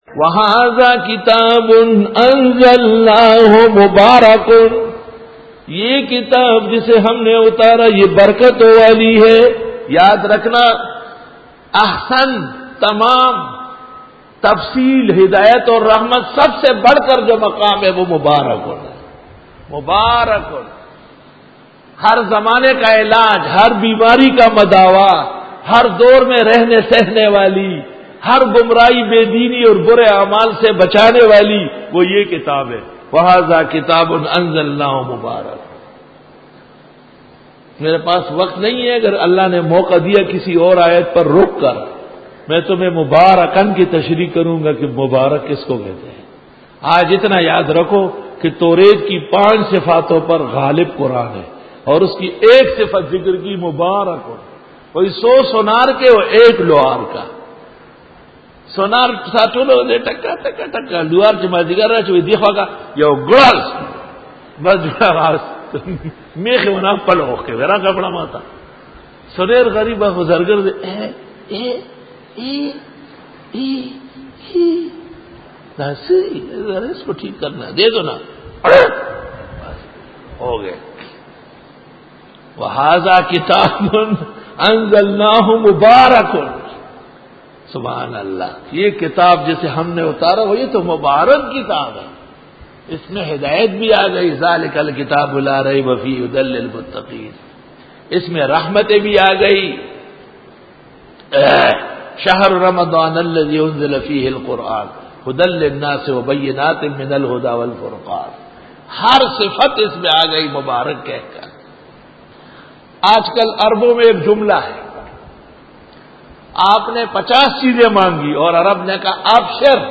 Dora-e-Tafseer 2008